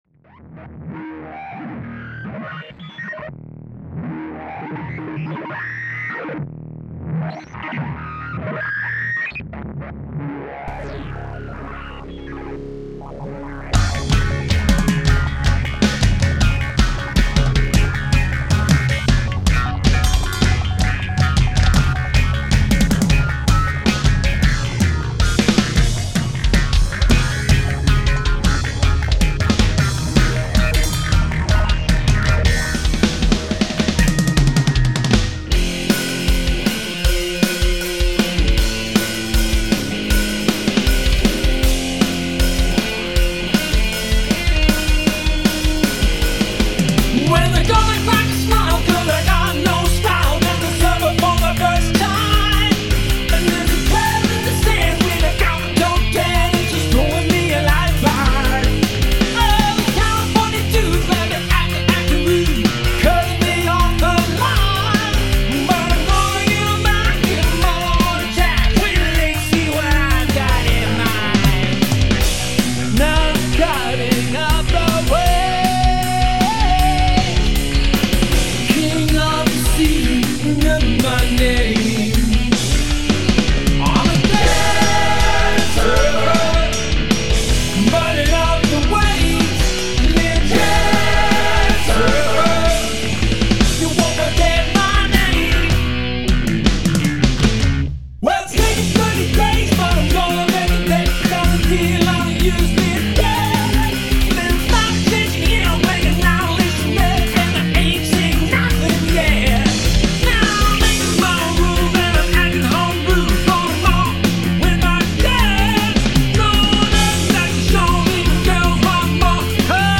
There's are some FX in the beginning, then the band starts t around 6 seconds in.